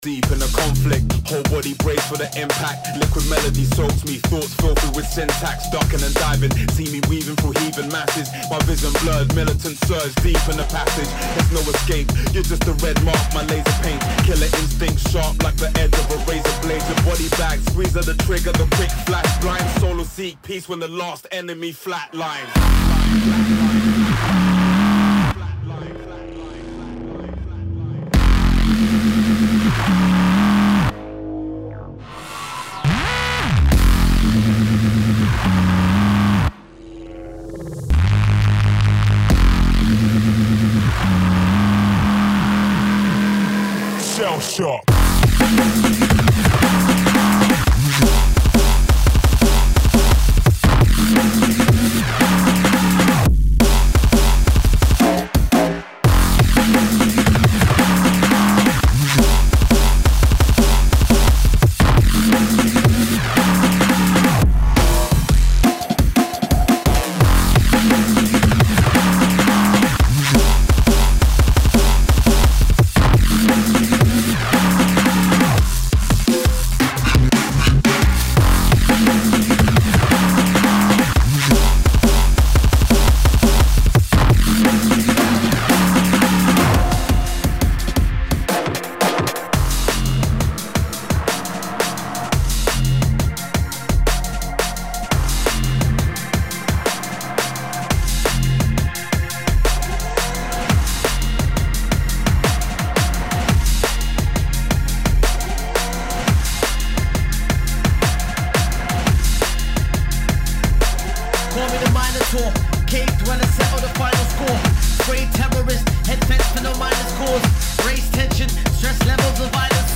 Стиль музыки: Neuro Funk